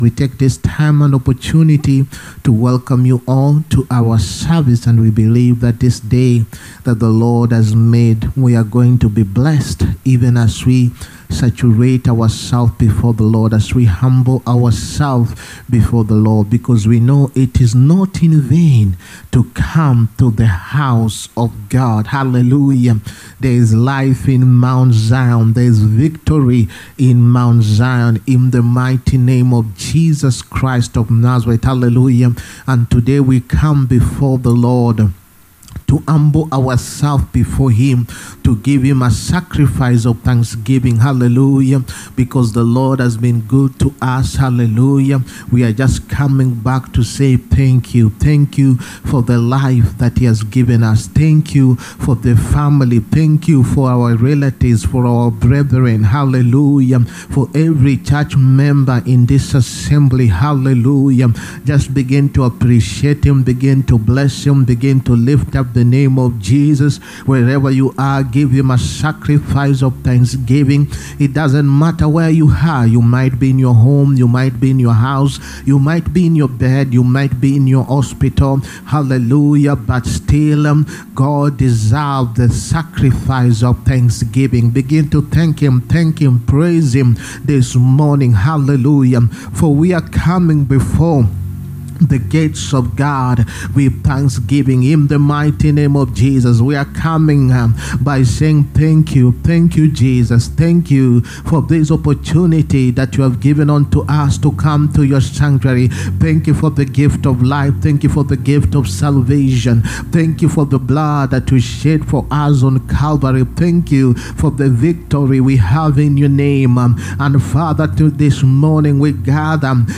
SUNDAY WORSHIP SERVICE. DISCOVERY OF YOUR IDENTITY IN CHRIST. 11TH MAY 2025.